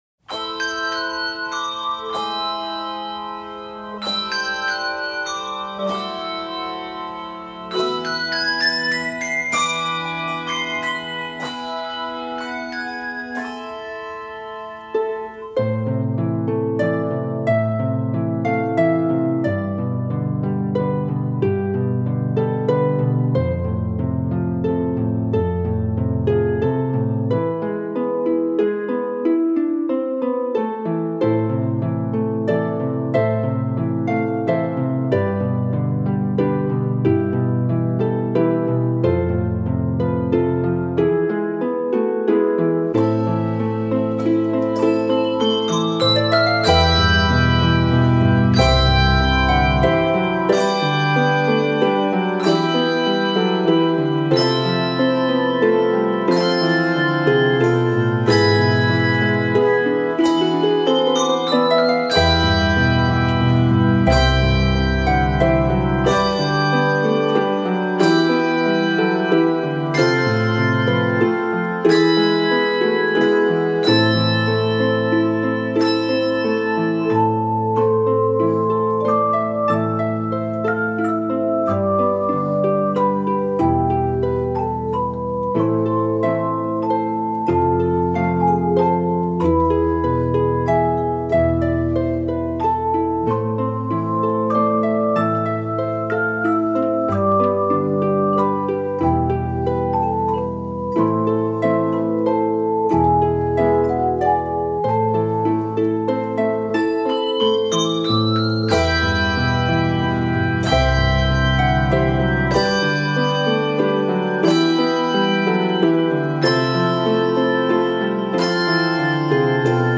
A hauntingly beautiful handbell introduction sets the scene
Key of a minor.